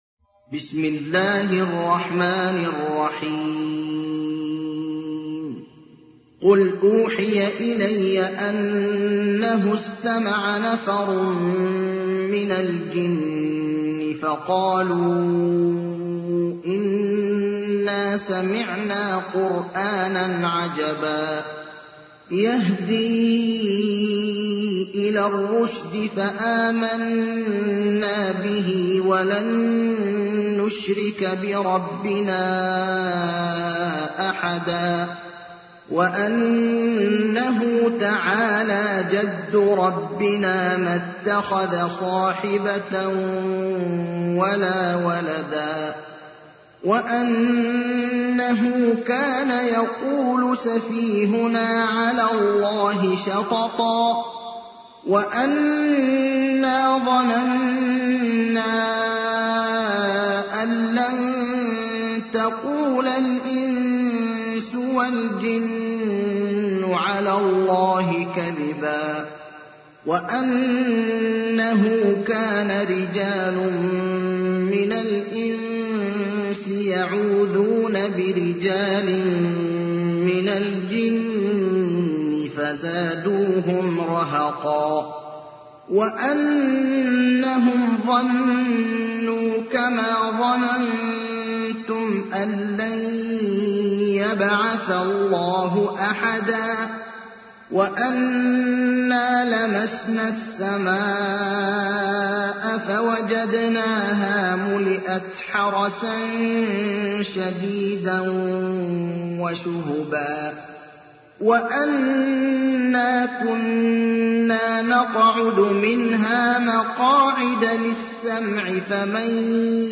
تحميل : 72. سورة الجن / القارئ ابراهيم الأخضر / القرآن الكريم / موقع يا حسين